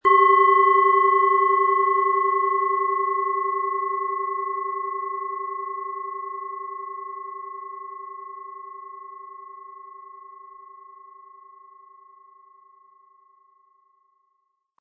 Tageston
Sie möchten den schönen Klang dieser Schale hören? Spielen Sie bitte den Originalklang im Sound-Player - Jetzt reinhören ab.
SchalenformBihar
HerstellungIn Handarbeit getrieben
MaterialBronze